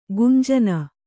♪ gunjana